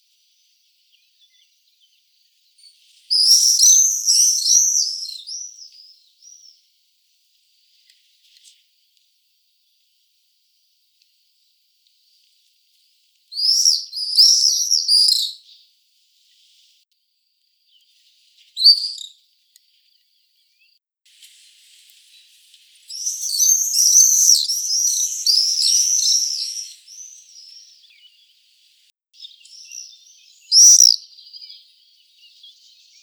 Apus apus - Swift - Rondone
DATE/TIME: 2/june/2013 (5 p.m.) - IDENTIFICATION AND BEHAVIOUR: Three swifts - well in sight - are flying over a small village, performing aerial pursuits. They are screaming and calling together.
Note that there are three call series produced by the bird flock and two single calls produced by one bird.